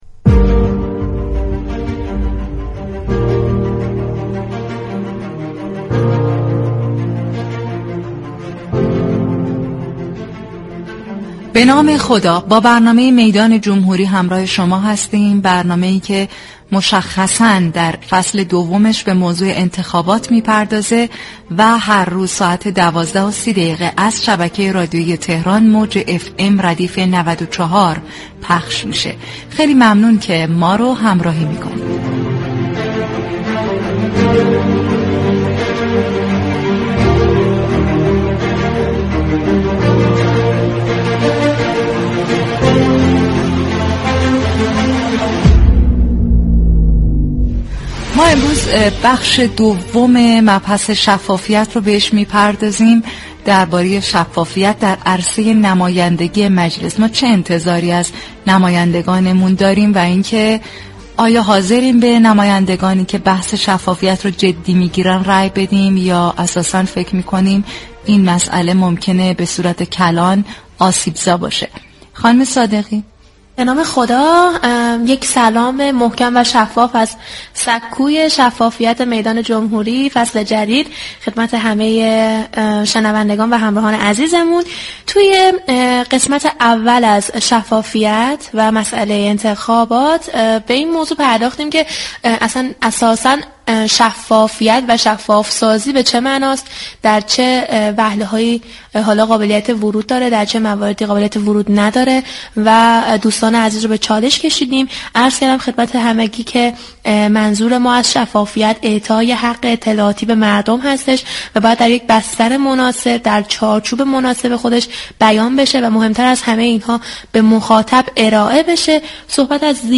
برنامه «میدان جمهوری» 8 اسفند با موضوع شفاف‌سازی عملكرد نمایندگان مجلس بر روی آنتن رادیو تهران رفت.